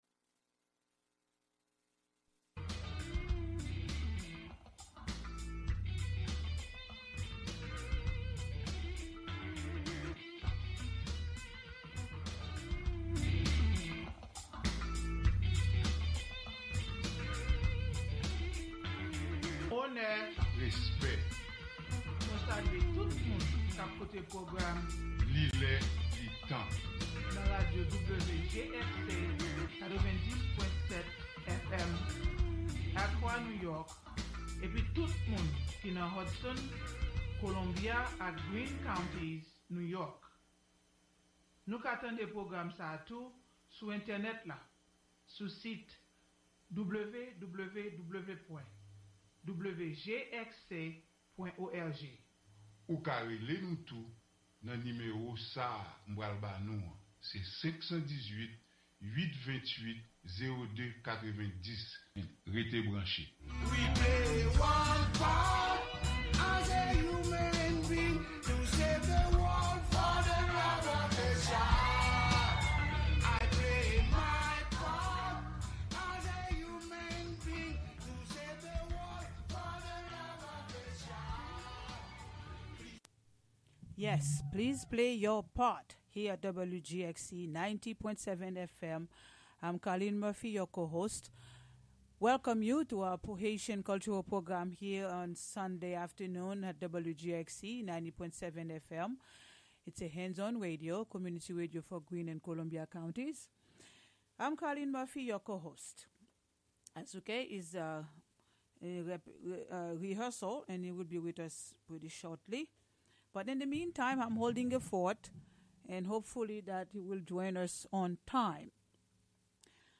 A weekly Creole language radio program, meaning "It’s the time, it’s the hour." The show's mission is to promote Haitian language, music, arts and culture; to bring awareness and to discuss Haiti’s history and its relevance in today’s world.